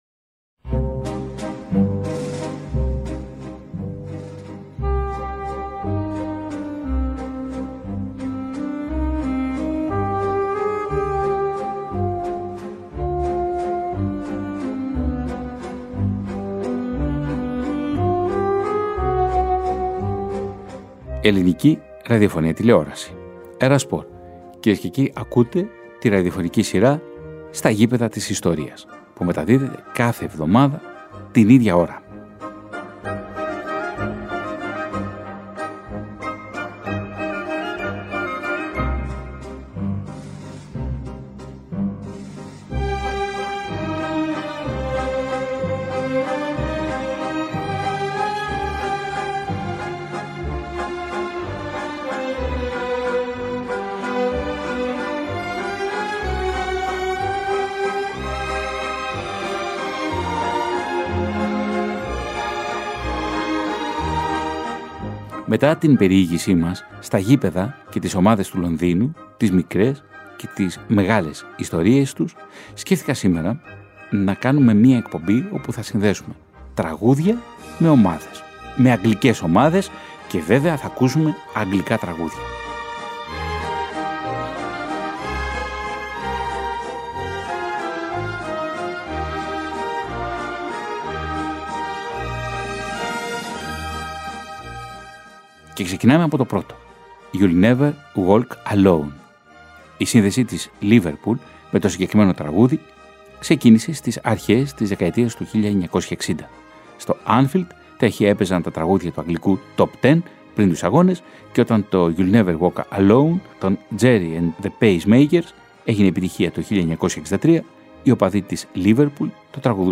O Φεβρουάριος στην ΕΡΑ ΣΠΟΡ είναι αφιερωμένος στο βρετανικό ποδόσφαιρο και συγκεκριμένα στις ομάδες του Λονδίνου. Τρία συν ένα ραδιοφωνικά ντοκιμαντέρ, οδοιπορικά στα λονδρέζικα γήπεδα αλλά και στις παμπ και στις μουσικές σκηνές της αγγλικής πρωτεύουσας, από τις αρχές της δεκαετίας του 1960 μέχρι και τις αρχές του 1980.
Η Θρυλική Μάχη της Τσέλσι με τη Λιντς στον Τελικό του FA Cup , ο Μπόμπι Μουρ και το σκάνδαλο της κλοπής του βραχιολιού, η Άρσεναλ και το νταμπλ του 1971, η Τότεναμ και το πρώτο ευρωπαϊκό τρόπαιο για αγγλική ομάδα, ο σκάνδαλο των “μαύρων πληρωμών” της Άρσεναλ, το αξέχαστο γκολ του Ρίκι Βίγια στον τελικό του FA Cup το 1981, η τελευταία μέρα του θρυλικού Χάιμπουρι το 2006, αλλά με ρίζες στο 1913, ο Μάλκολμ Άλισον και η αποτυχία της Κρίσταλ Πάλας , οι οπαδικές συγκρούσεις των ’60s & ’70s είναι μερικά από τα θέματα που θα έρθουν στο φως, μαζί με σπάνια ηχητικά ντοκουμέντα από τα γήπεδα του Λονδίνου και τα θρυλικά τραγούδια της εποχής.